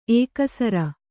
♪ ēkasara